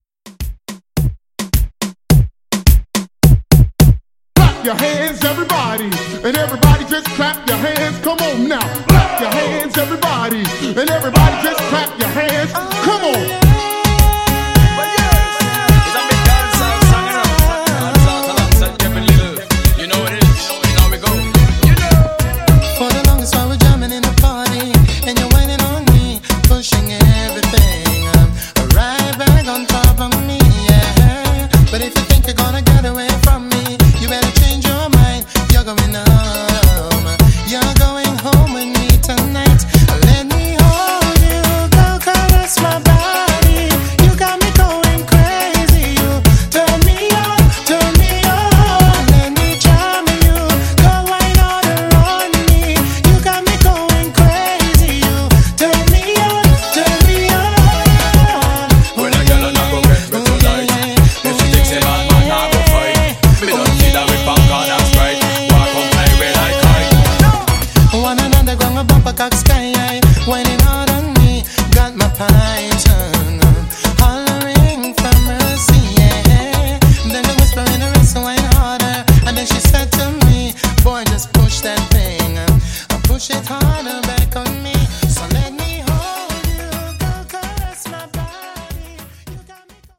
Genre: 80's
Clean BPM: 125 Time